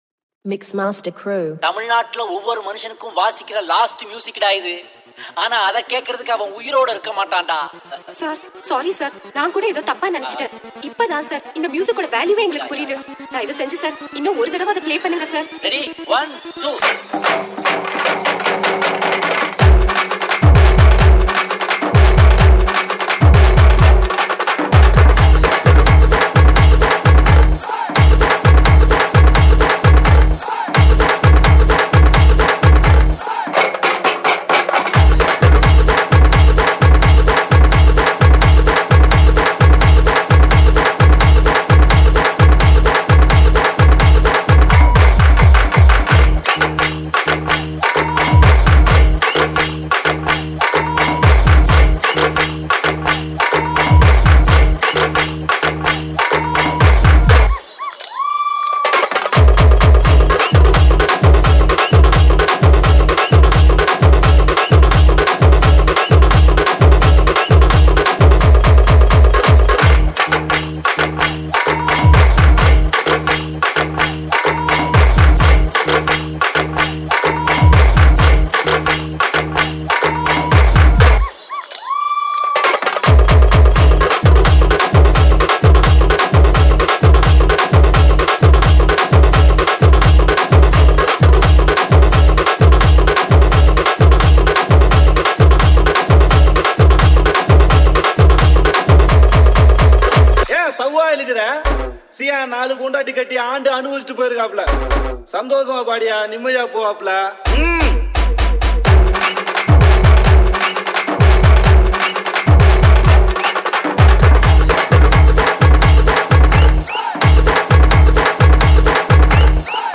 ALL TAMIL ROMANTIC DJ REMIX